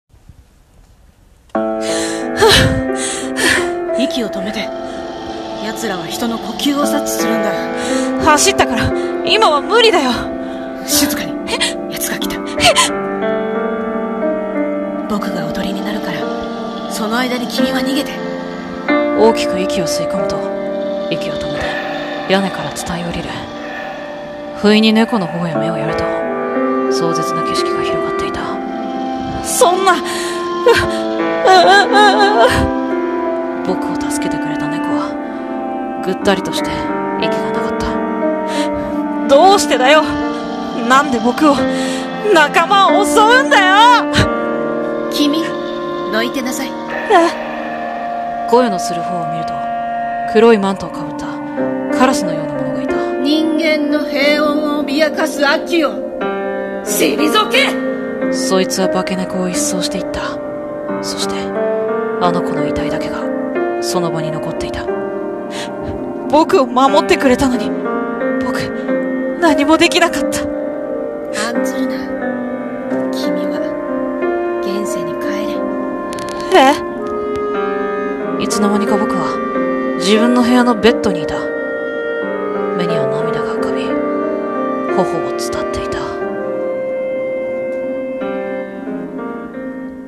3人声劇台本】「妖の境界線」